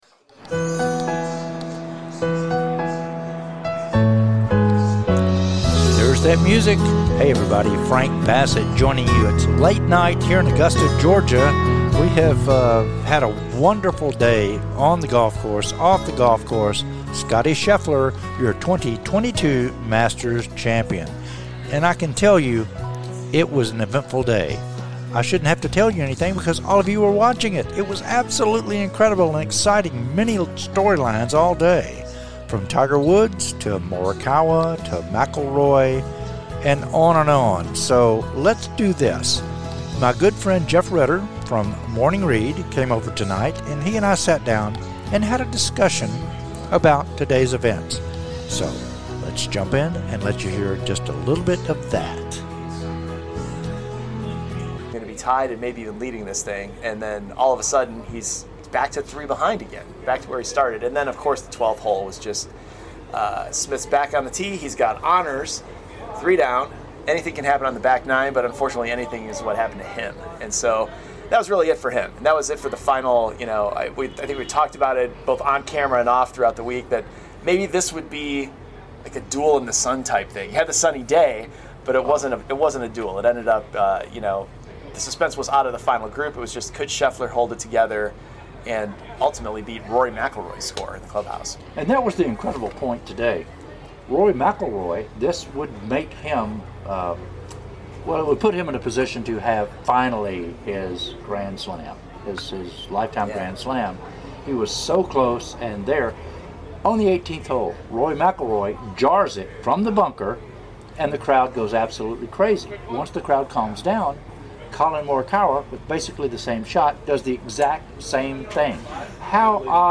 "LIVE" FROM THE MASTERS "IT'S A WRAP"